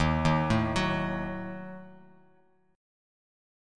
D#dim chord